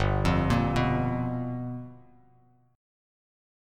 G7#9 chord